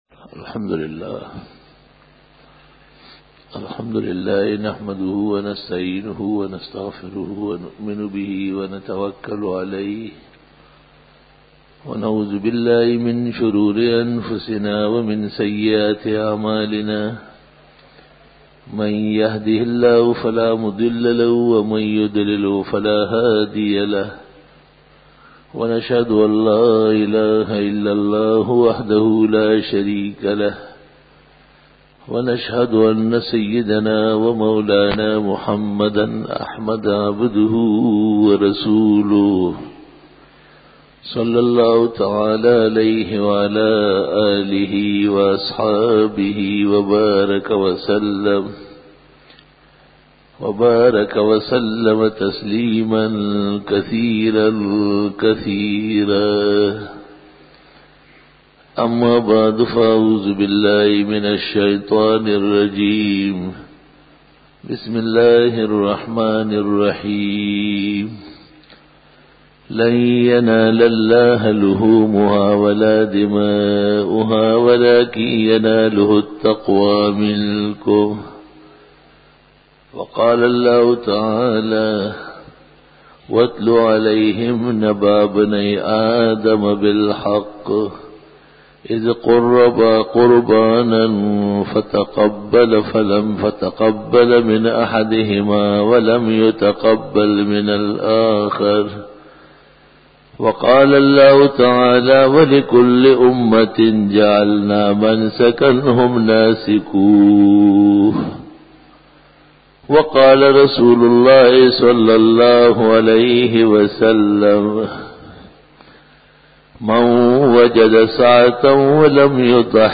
بیان جمعۃ المبارک